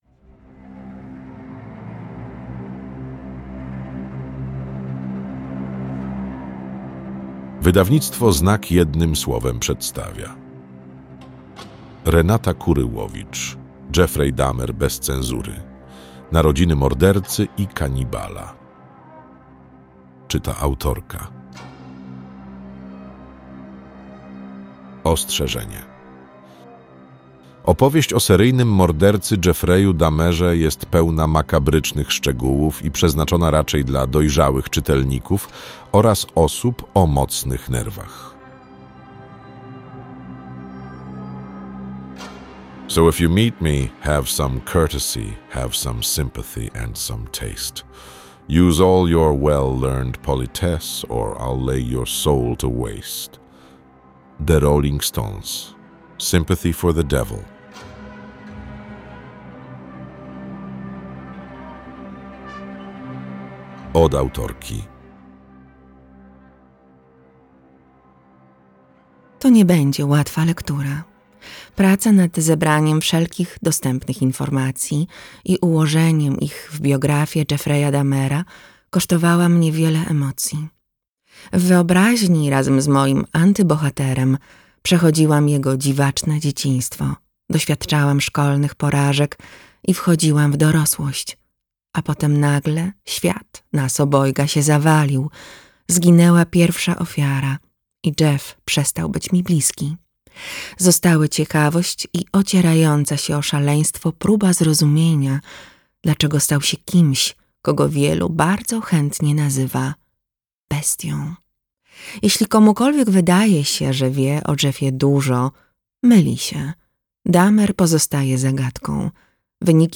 audiobook + książka